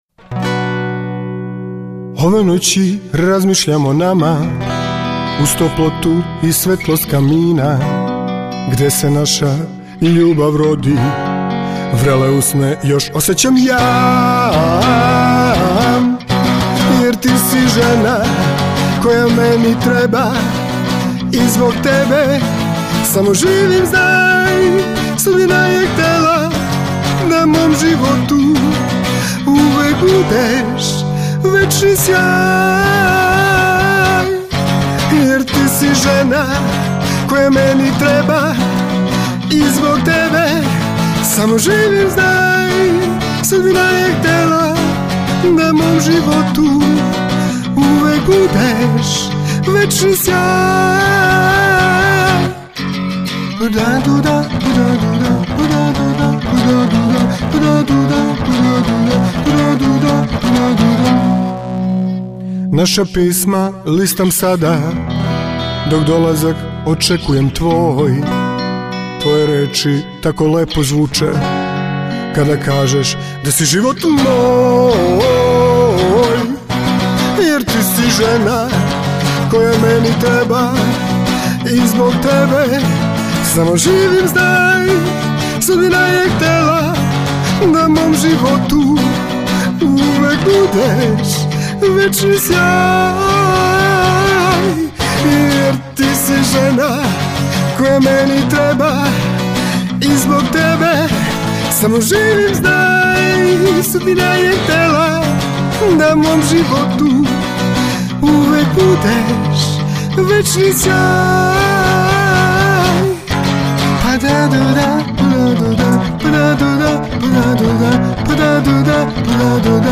gitaru
harmoniku